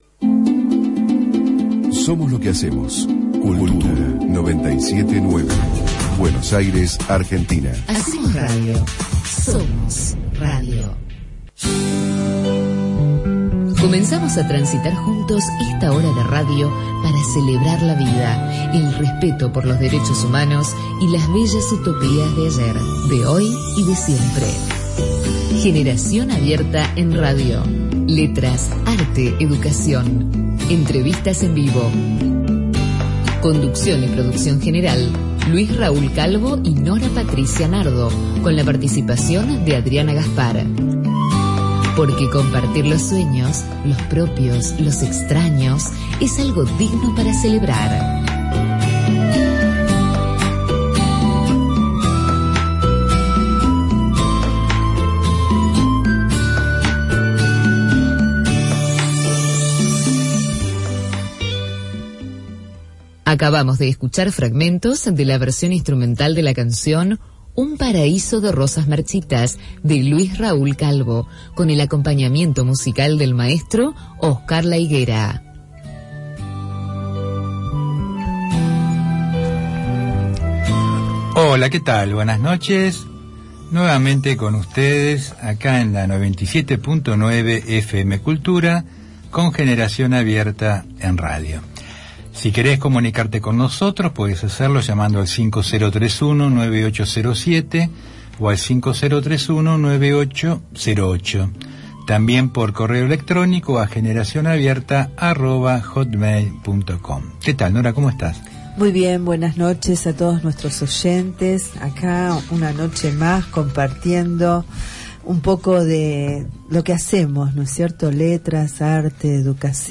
Por la Radio AM 1010 “Onda Latina” , Buenos Aires, Argentina.